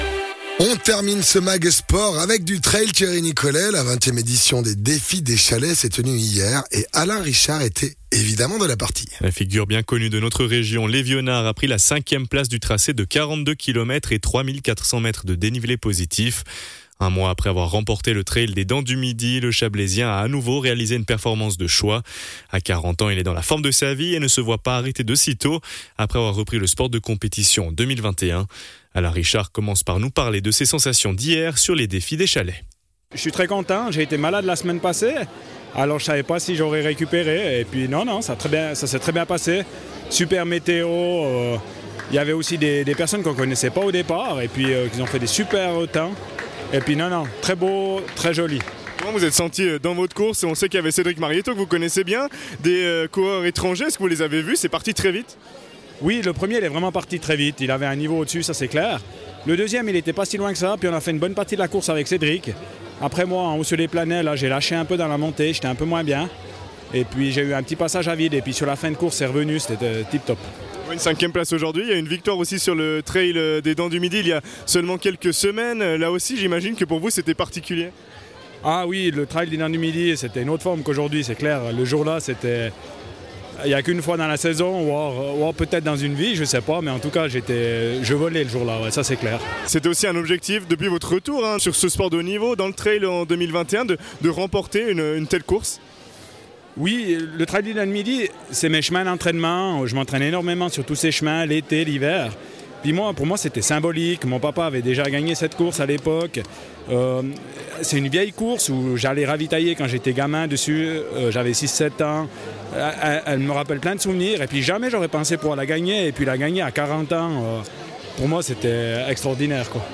athlète